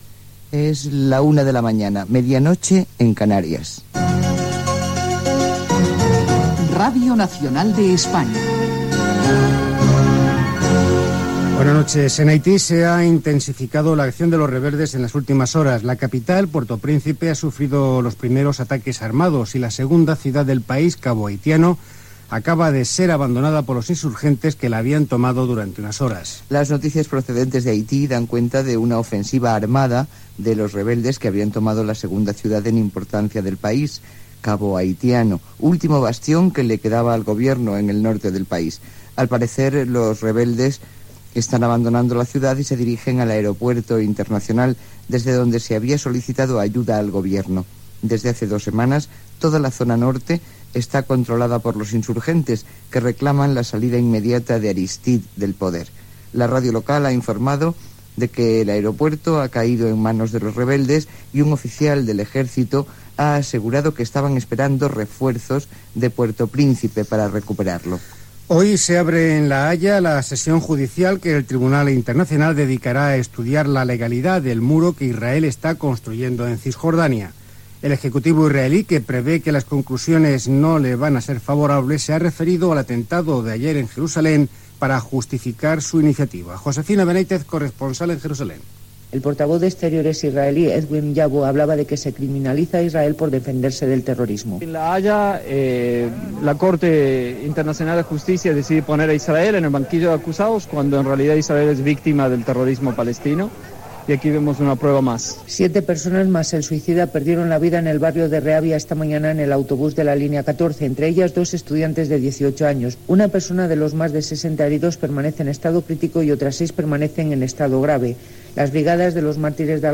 Hora, indicatiu dels informatius, Haití, el mur construït per Israel al tribunal internacional de La Haia.
Informatiu